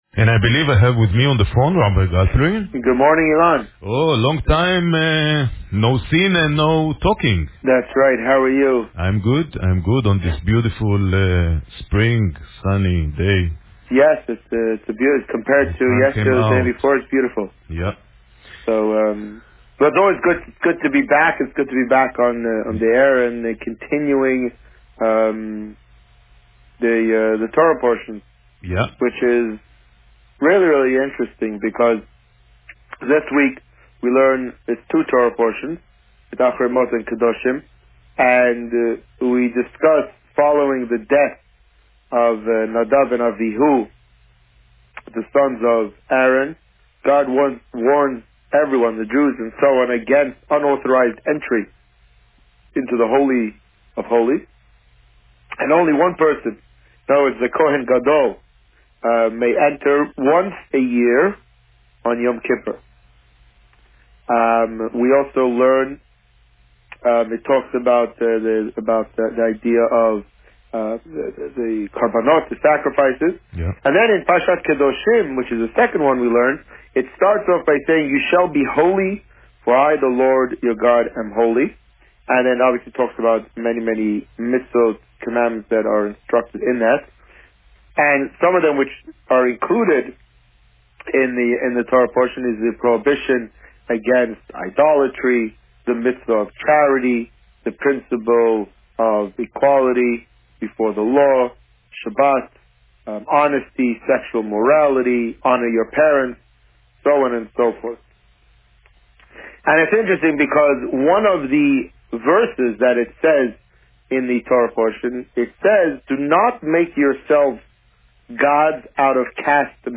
This week, the Rabbi spoke about Parsha Acharei-Kedoshim and the upcoming May 11th Shabbaton. Listen to the interview here.